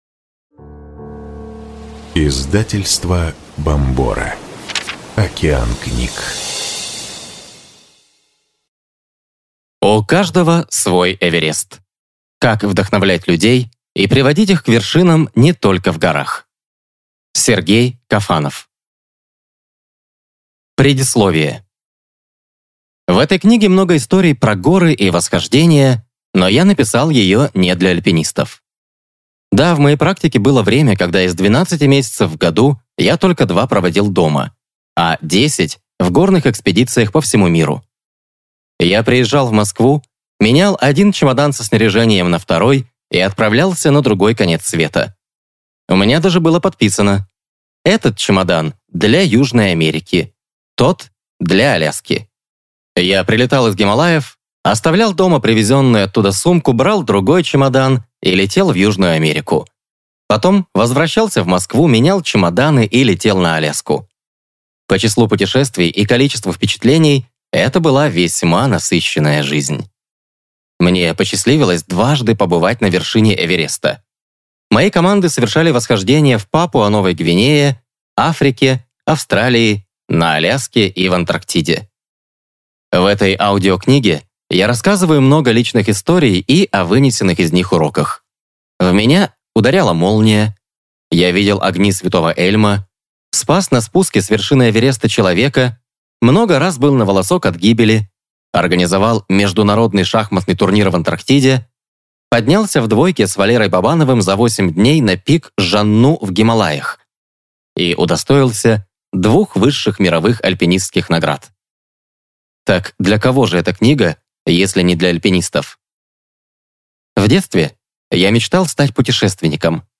Аудиокнига У каждого свой Эверест. Как опыт реальных восхождений помогает вдохновлять команды и управлять проектами | Библиотека аудиокниг